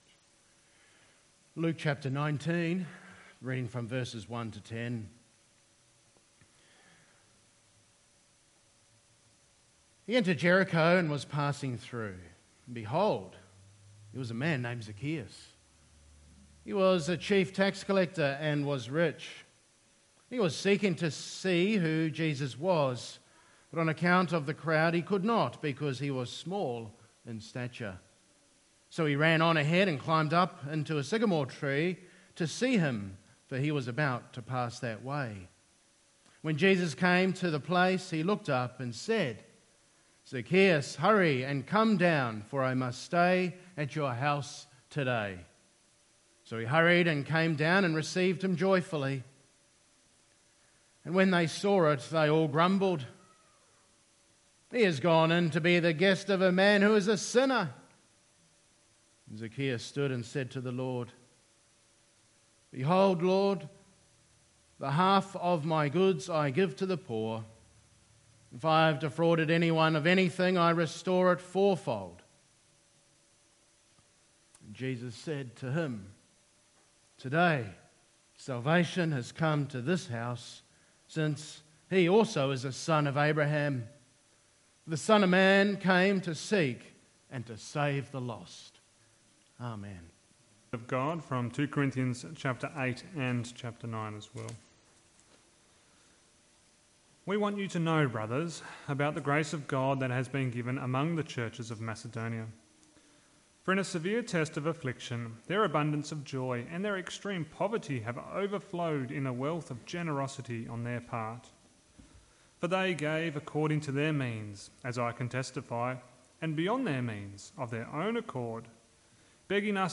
Giving | Sermon Topics | Christian Reformation Community Church